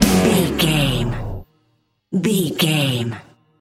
Aeolian/Minor
F#
drums
electric guitar
bass guitar
hard rock
aggressive
energetic
intense
nu metal
alternative metal